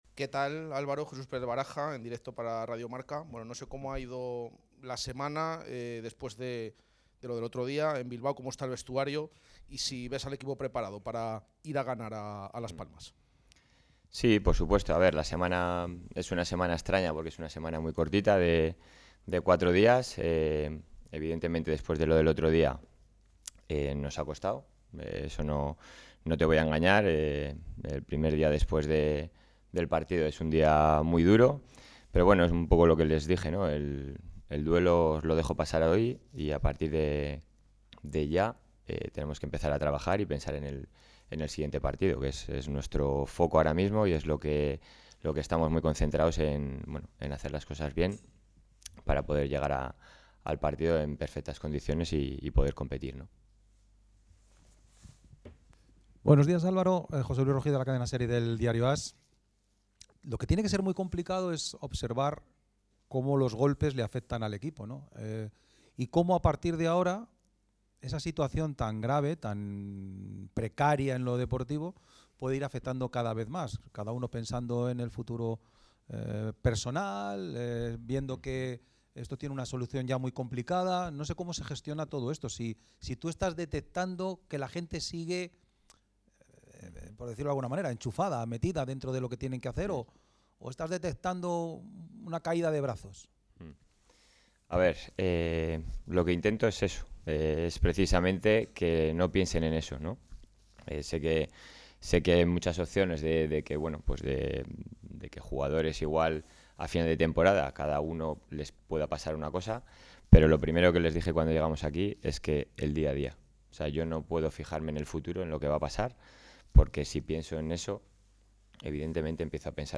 rueda de prensa previa al partido